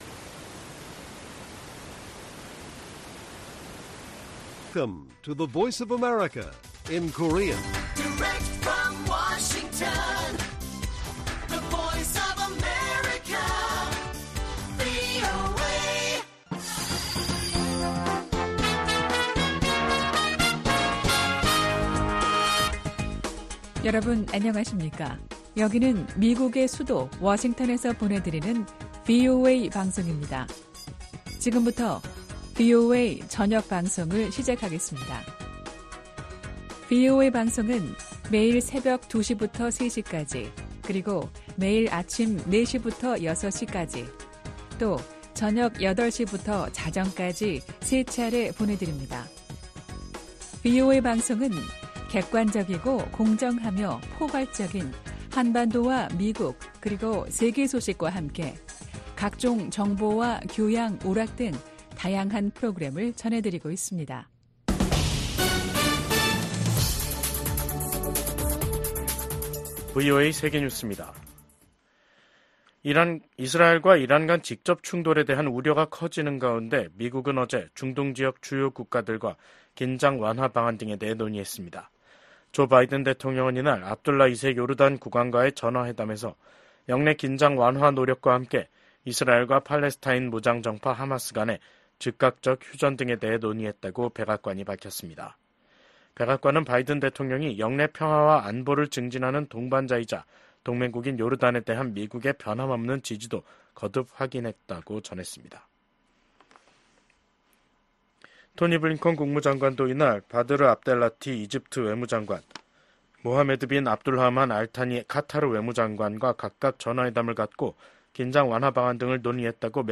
VOA 한국어 간판 뉴스 프로그램 '뉴스 투데이', 2024년 8월 6일 1부 방송입니다. 북한이 신형 전술 탄도미사일 발사대를 공개한 것과 관련해 미국의 전문가들은 북한의 점증하는 위협에 맞서 탄도미사일 방어 역량을 강화해야 한다고 지적했습니다. 압록강 유역 수해 복구에 전 사회적 인력 동원에 나선 북한이 외부 지원 제의는 일절 거부하고 있습니다.